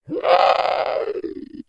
Громкий выкрик червяка